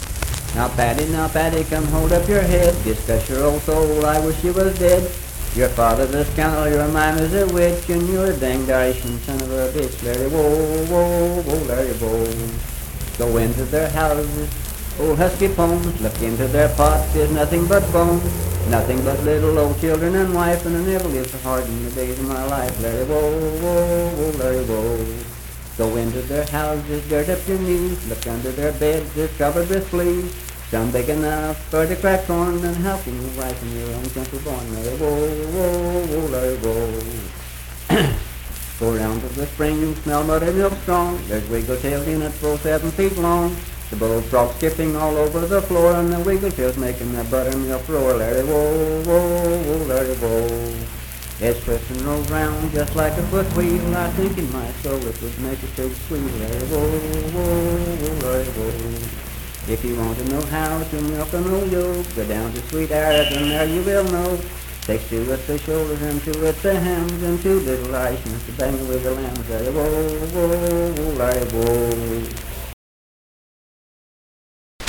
Unaccompanied vocal music performance
Ethnic Songs
Voice (sung)
Calhoun County (W. Va.)